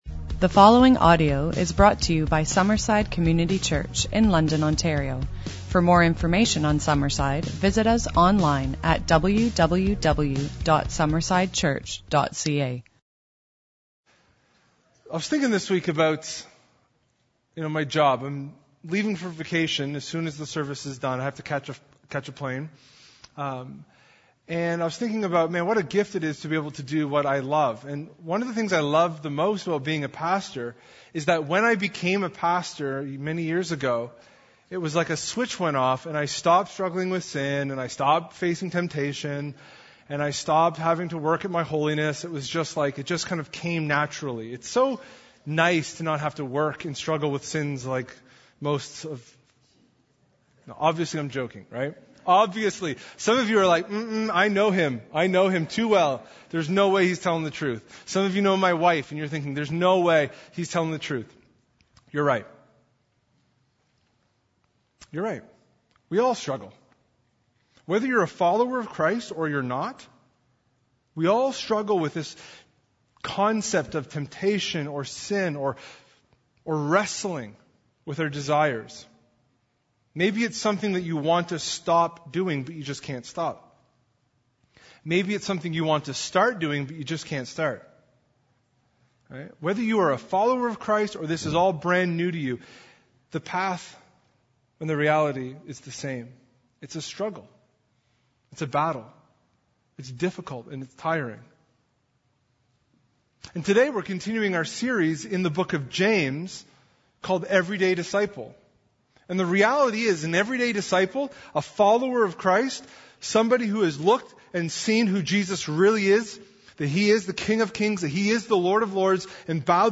In Sunday’s teaching